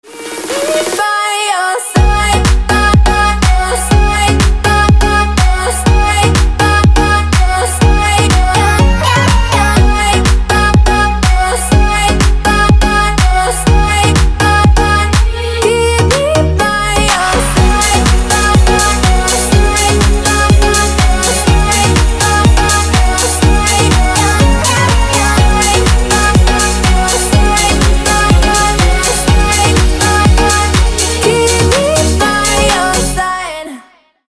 • Качество: 224, Stereo
женский вокал
dance
club